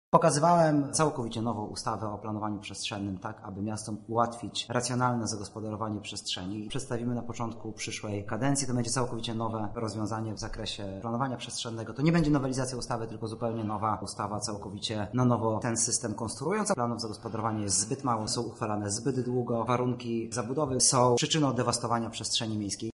O projekcie ustawy mówi Artur Soboń, wiceminister Infrastruktury i Rozwoju: